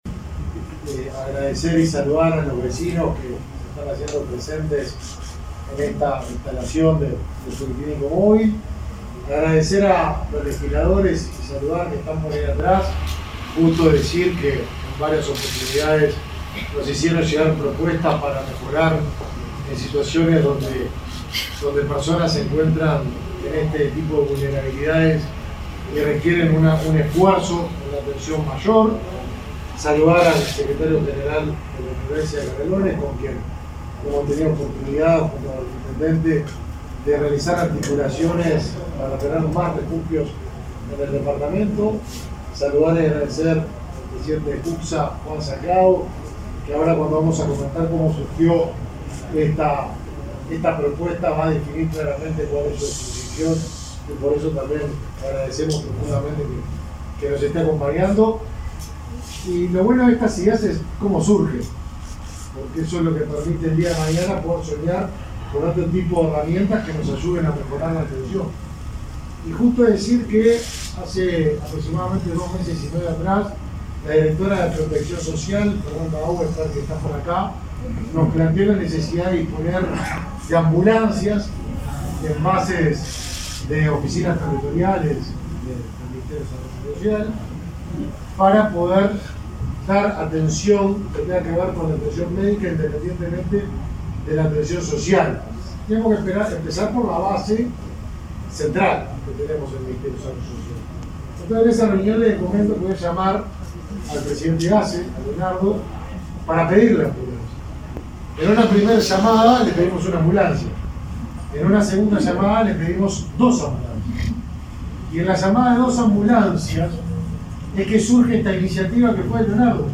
Palabras del ministro Lema y del presidente de ASSE, Leonardo Cipriani